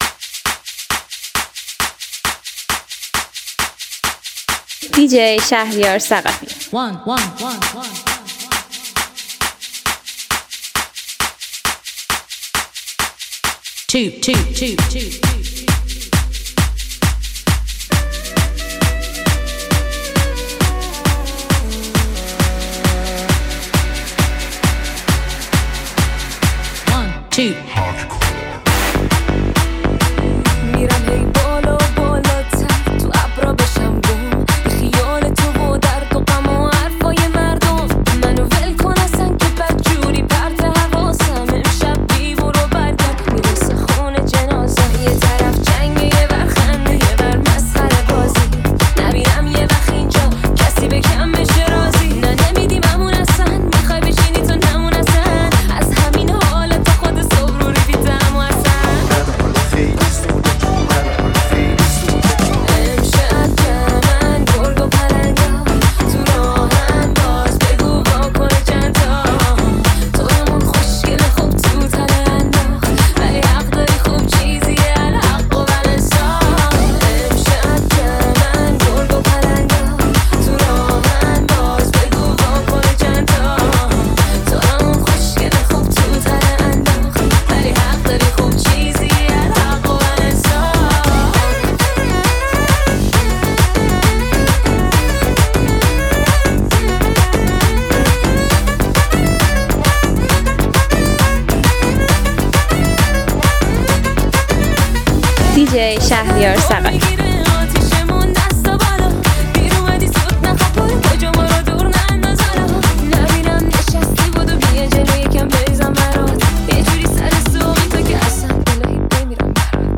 ریمیکس شاد